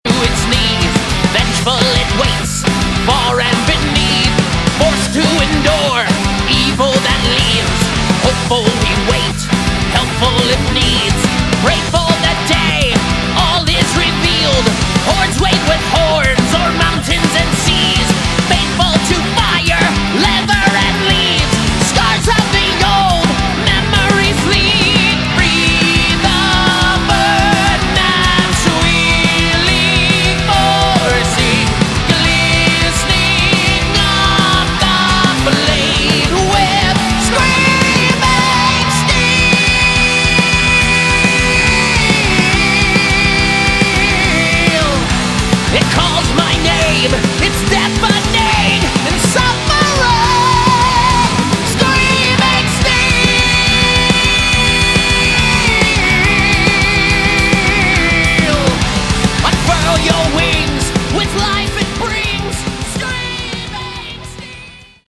Category: Melodic Metal
lead & backing vocals
Guitars
bass
drums
guitar solos